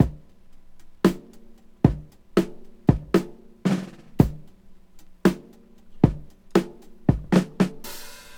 • 114 Bpm Drum Beat E Key.wav
Free breakbeat sample - kick tuned to the E note. Loudest frequency: 525Hz
114-bpm-drum-beat-e-key-wiP.wav